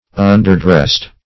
Underdressed \Un`der*dressed"\, a.